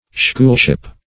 Search Result for " schoolship" : The Collaborative International Dictionary of English v.0.48: Schoolship \School"ship`\, n. A vessel employed as a nautical training school, in which naval apprentices receive their education at the expense of the state, and are trained for service as sailors.